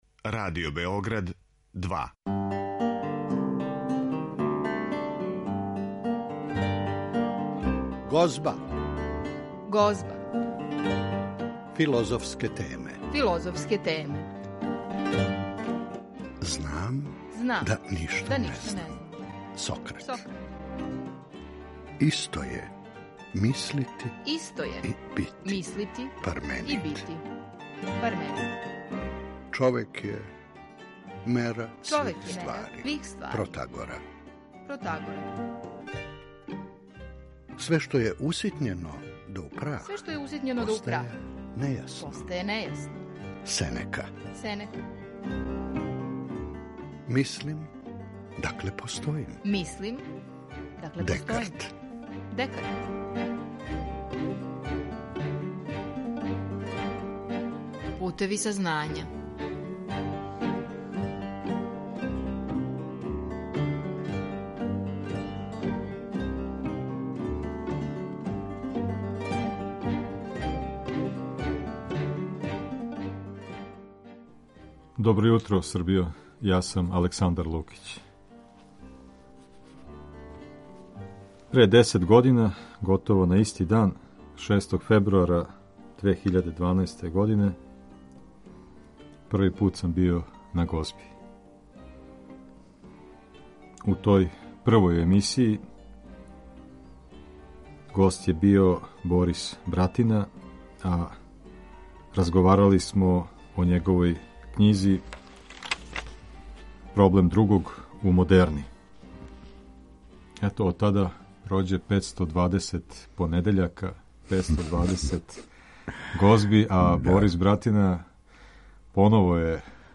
Тим поводом разговарамо са њим о проблемима сопства и другости.